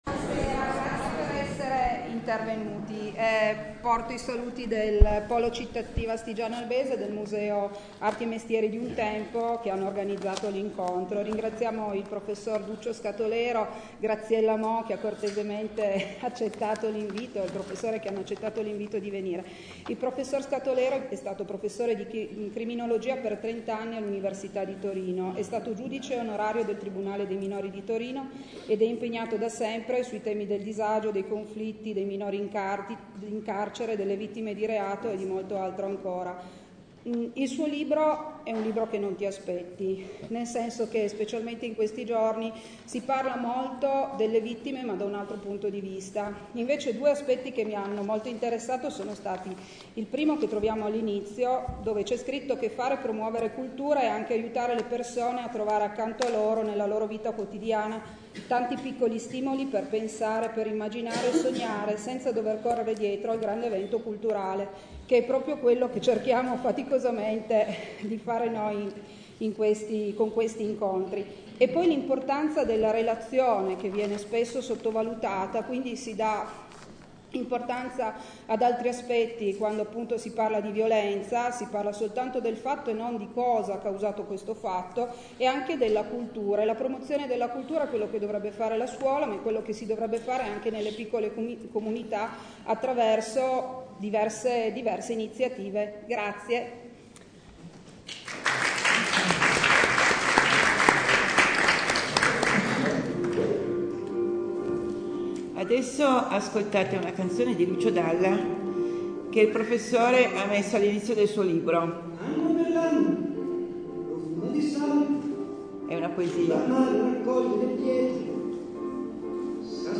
REGISTRAZIONE AUDIO DELL’INCONTRO